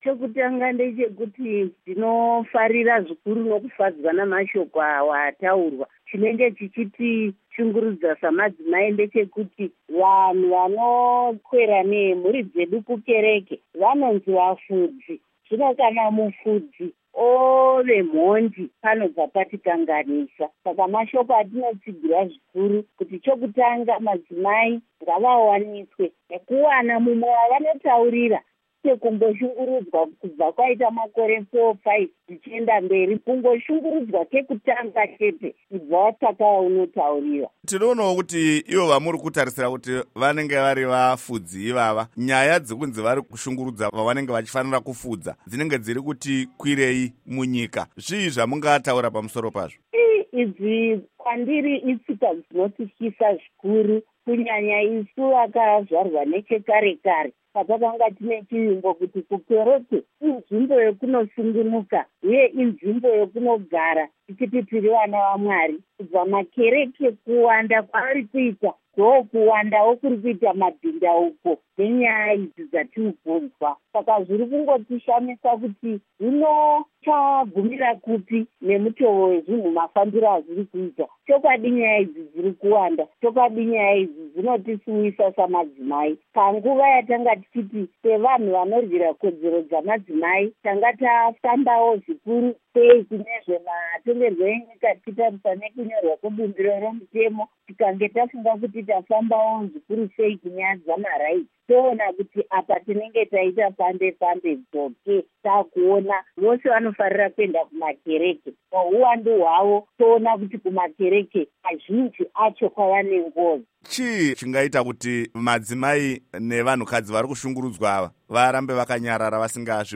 Hurukuro naAmai Lucia Matibenga